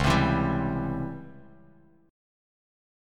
C#mM7b5 chord